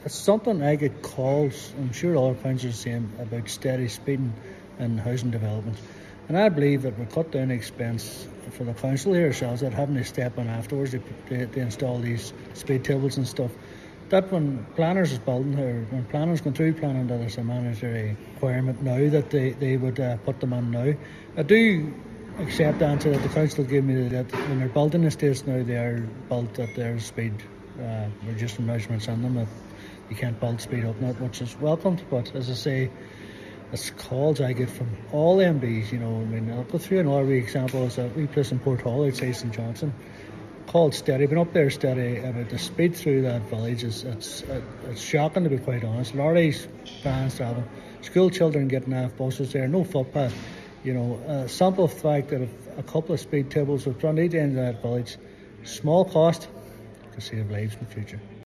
He says simple measures could ultimately save lives: